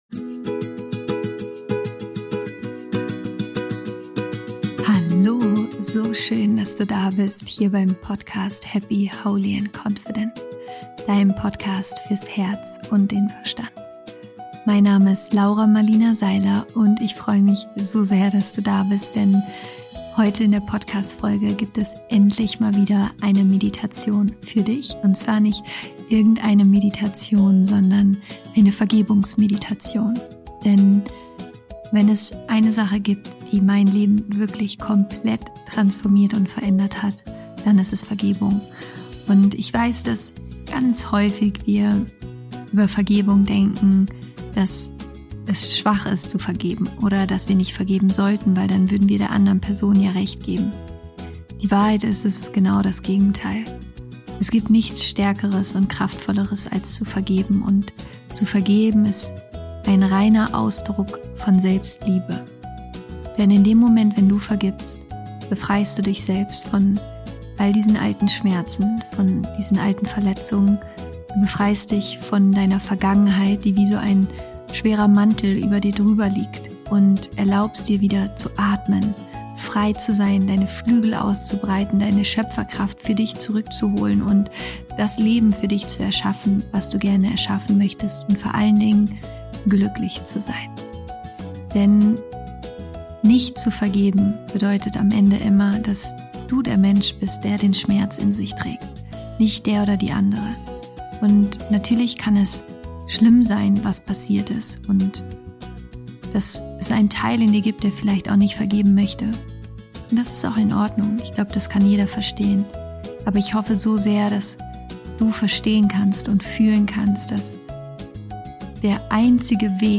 Darum erwartet dich heute im Podcast eine wundervolle Vergebungsmeditation. Die Meditation unterstützt dich dabei, dich von altem Schmerz zu befreien, die Vergangenheit loszulassen und auf emotionaler Ebene zu vergeben.